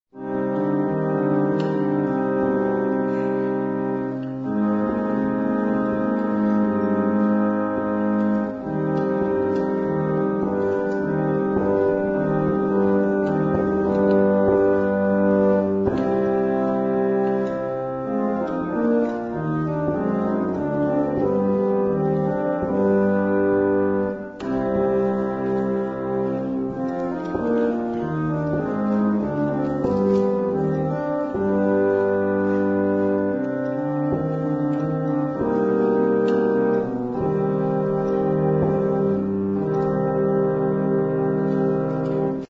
Zde jsou uvedeny ukázky skladeb, které zazněli na koncertě.